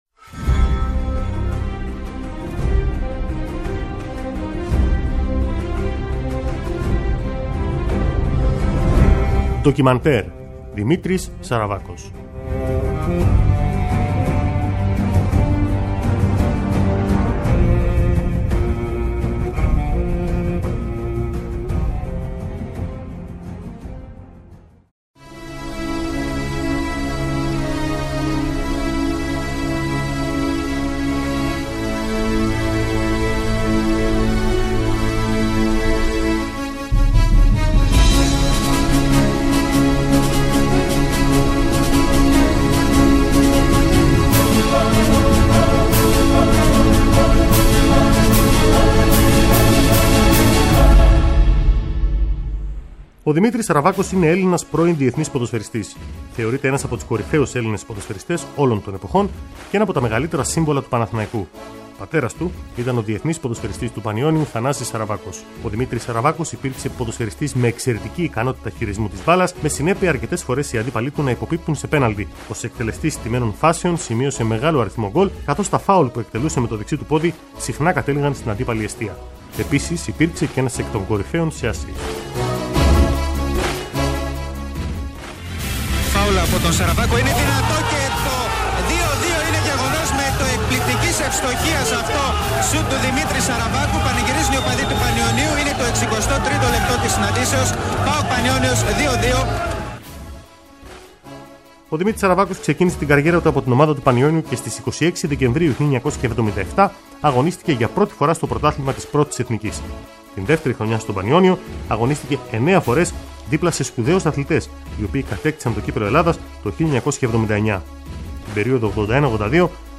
Ντοκιμαντέρ
Μια εκπομπή με ιστορικές αναφορές σε πρόσωπα και γεγονότα που σημάδεψαν τον αθλητισμό εντός κι εκτός Ελλάδας. Έρευνα και σπάνια ηχητικά ντοκουμέντα, σε μια σειρά επεισοδίων από την ΕΡΑσπορ.